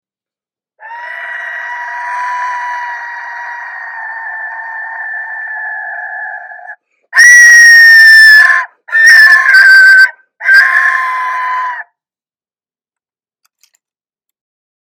Aztec Death Whistle scary frightening sounds alien extraterrestrial skull!!!
Large Aztec Death whistle
The Aztec Death whistle, hand tuned to produce the most frightening scariest sound.
The Aztec Death whistle is a hand crafted musical instrument producing the loudest, scariest, terrifying sound around.